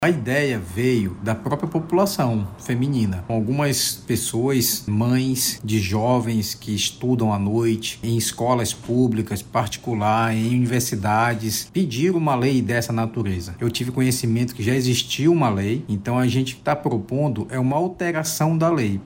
A proposta é inspirada em uma política adotada nas capitais brasileiras, como São Paulo, onde a implantação dessa medida ajudou a reduzir situações de vulnerabilidades dos usuários do Transporte Público. O parlamentar destaca, ainda, que o projeto foi uma solicitação das usuárias do modal.